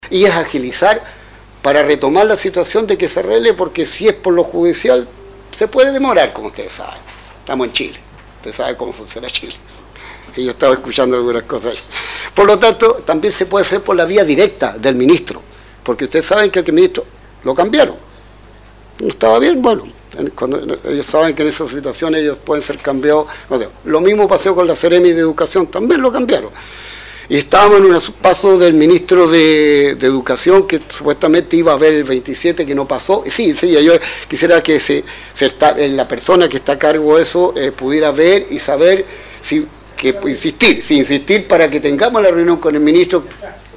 ante el concejo municipal, en una reunión ordinaria que tuvo lugar la semana pasada